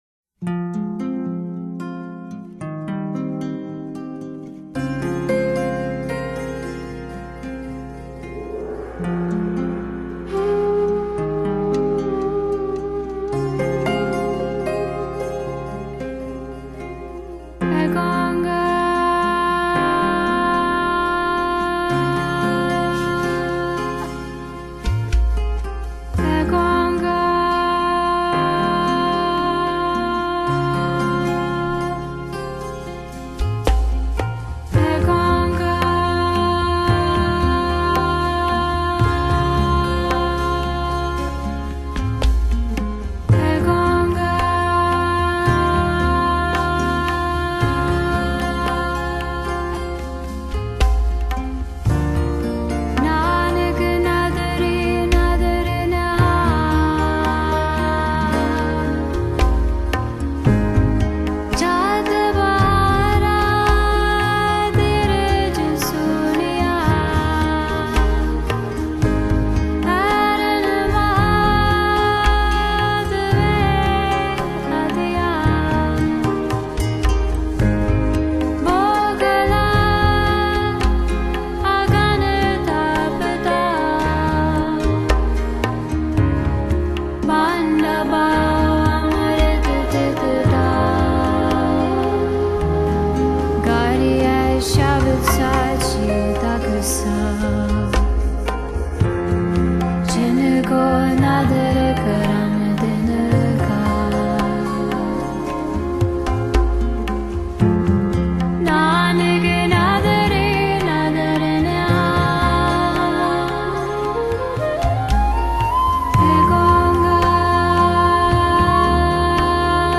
音樂風格︰New Age, World Music | 1CD |